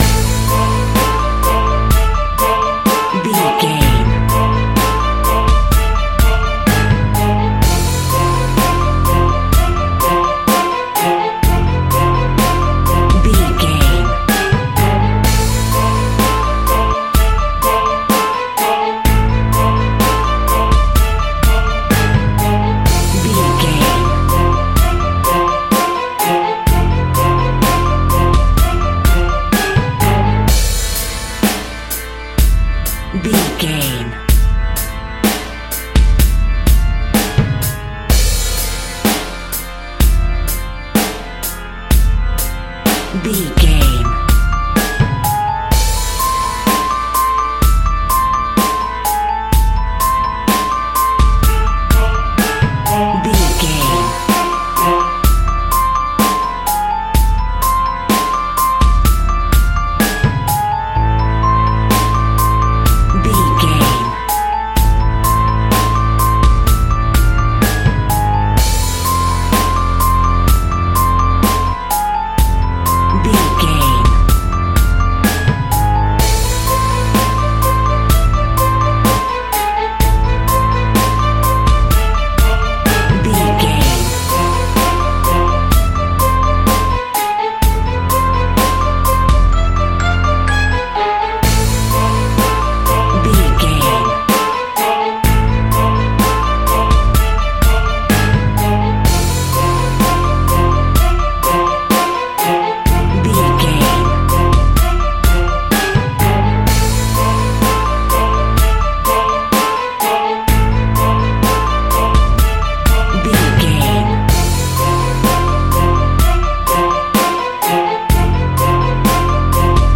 In-crescendo
Thriller
Aeolian/Minor
scary
tension
ominous
dark
eerie
strings
synthesiser
piano
drums
bass guitar
organ
pads